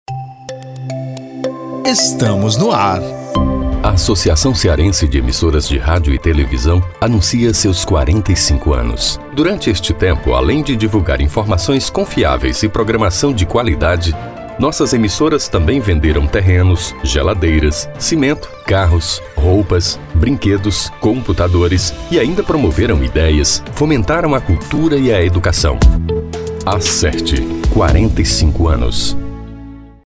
Um spot especial para comemorar os 45 anos da ACERT foi produzido e está sendo veiculado pelas emissoras afiliadas, festejando a vitoriosa existência da entidade.
SPOT_45_ANOS.mp3